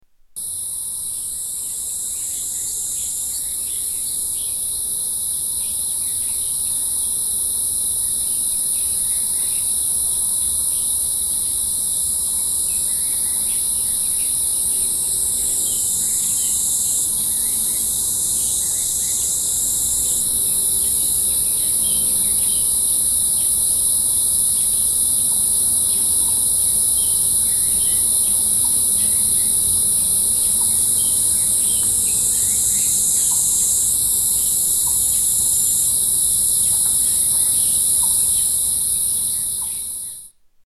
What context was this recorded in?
Summer sunset at Yang Ming Mountain